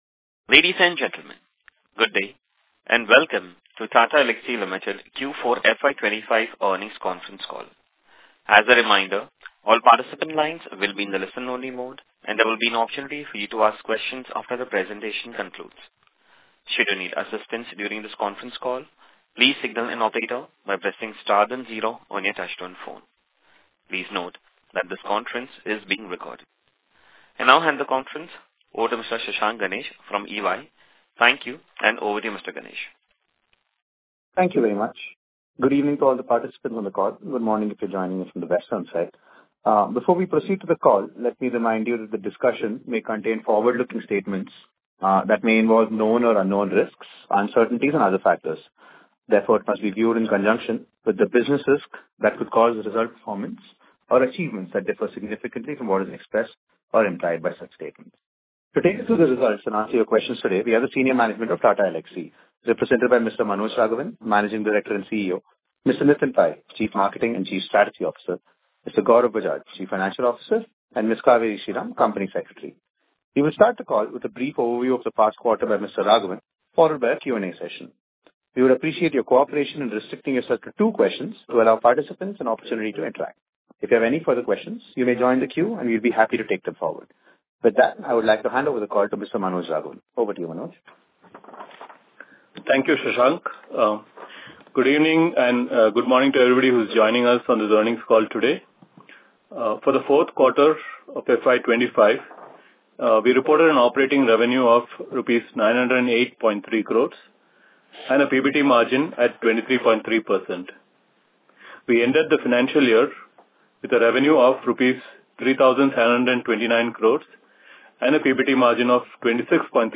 Concalls
Tata-Elxsi-Q425-Investor-call.mp3